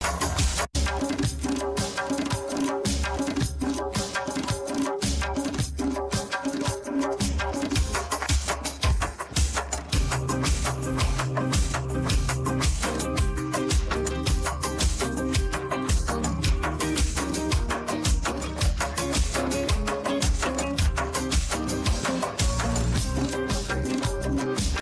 هوش مصنوعی پیشرفته fadr برای حذف صدای خواننده